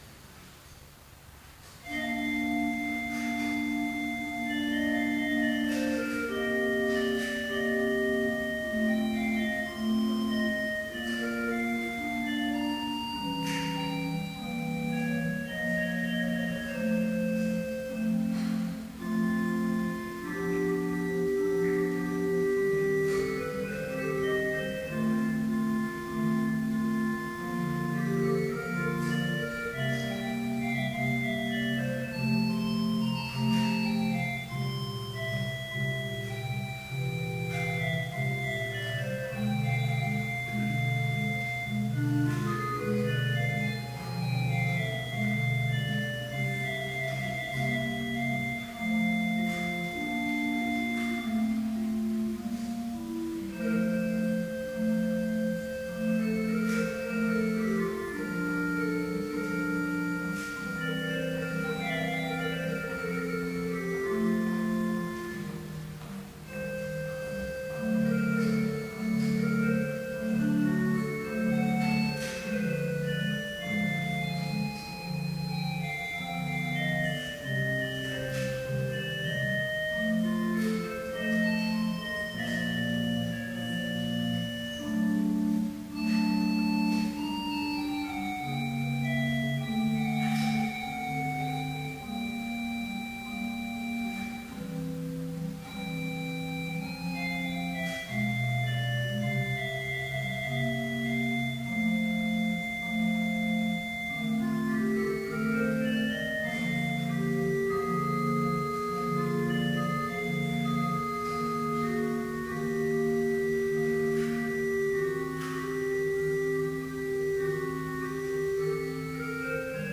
Complete service audio for Evening Vespers - September 12, 2012